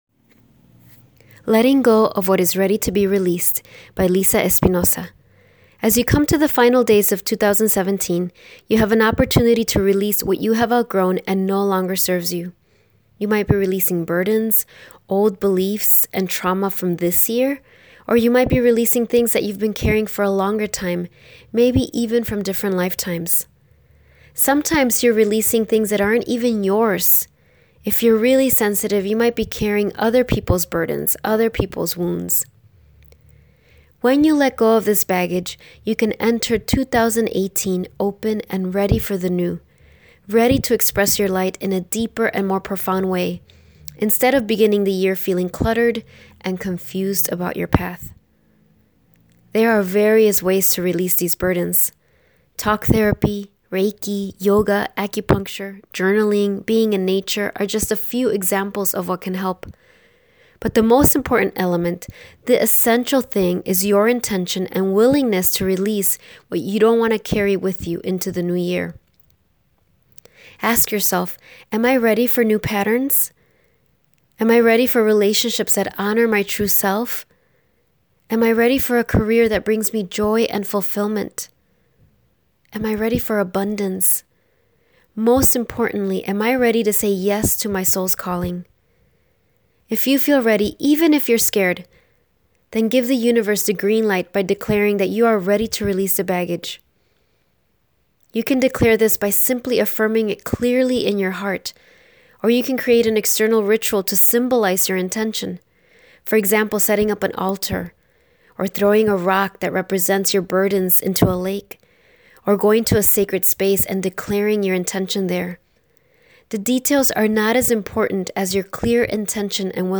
Audio version of blog